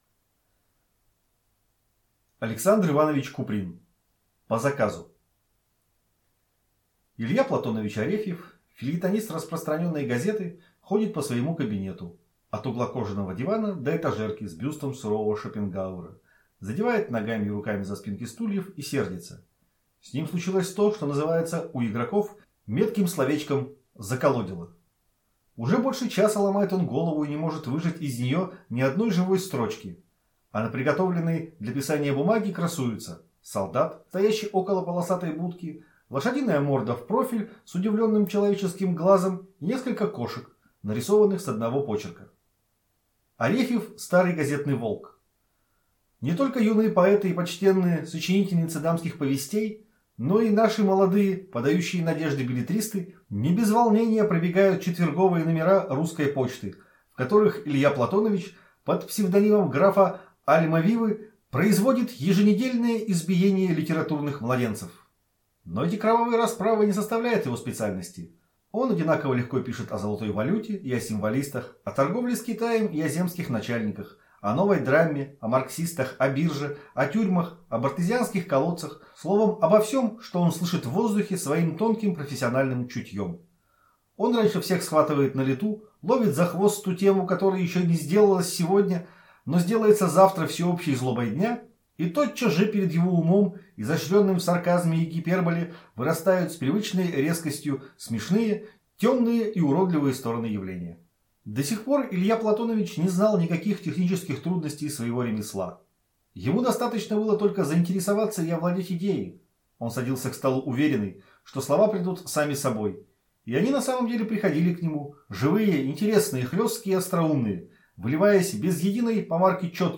Аудиокнига По заказу | Библиотека аудиокниг